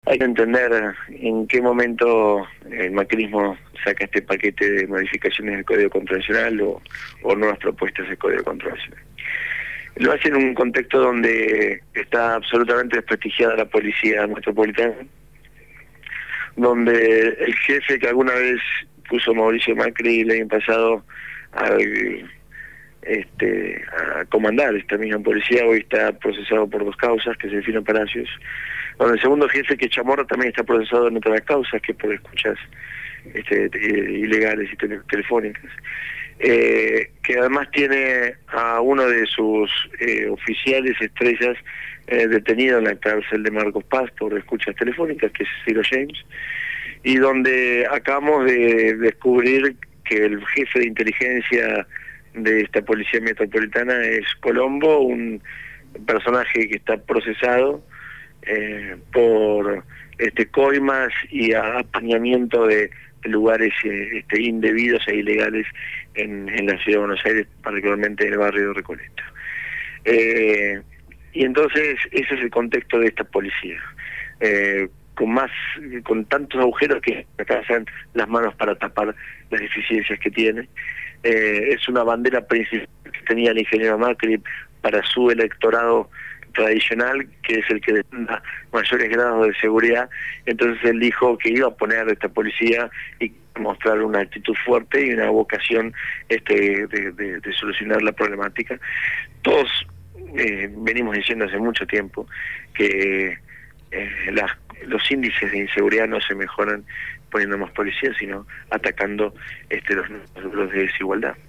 El Legislador porteño Juan Cabandié habló en «Punto de Partida» (Lunes a Jueves, de 8 a 10 de la mañana).